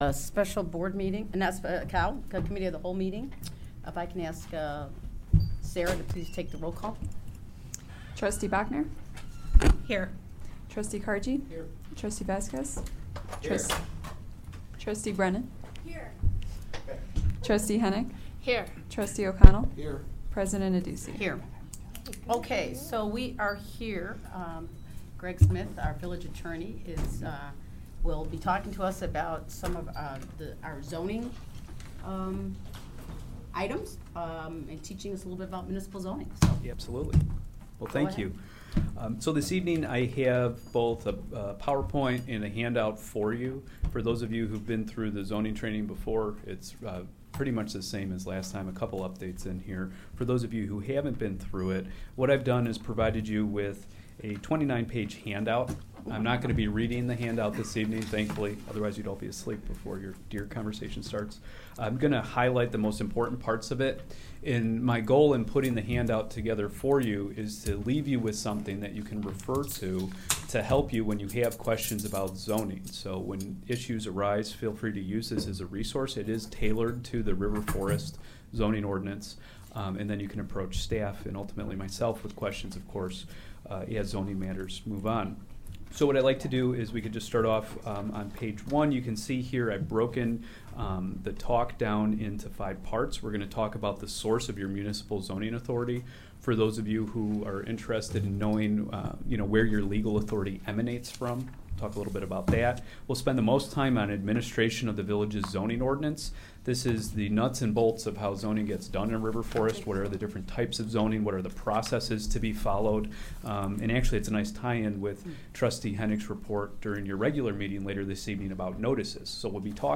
Development Review Board Meeting
07::30PM, Village Hall - 400 Park Avenue - River Forest - IL - 1st Floor - Community Room